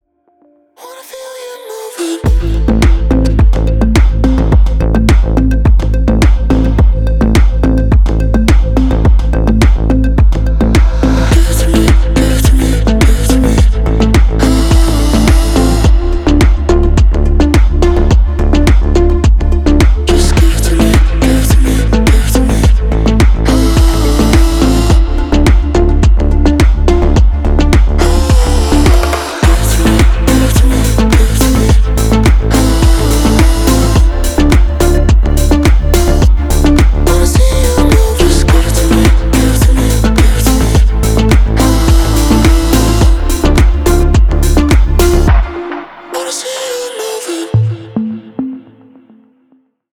• Качество: 320, Stereo
deep house
Midtempo
чувственные
Чувственная электронная музыка